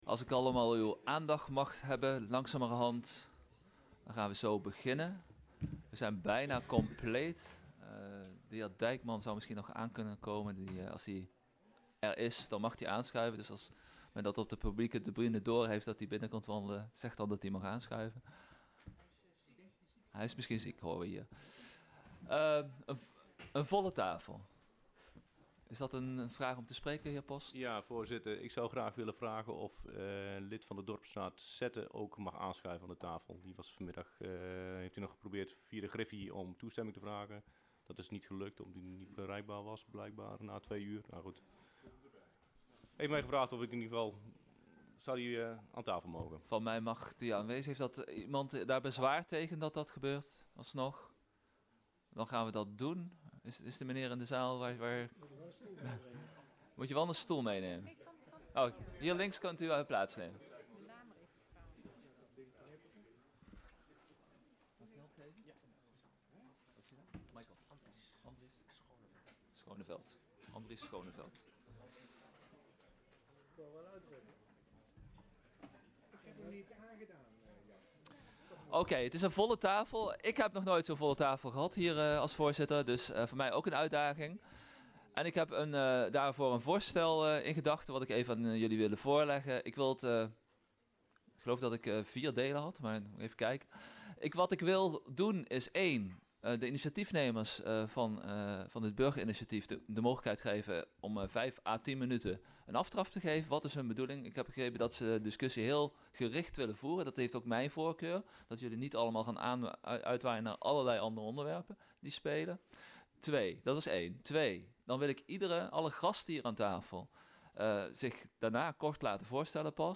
Locatie gemeentehuis Elst Voorzitter dhr.
Ronde Tafel Gesprek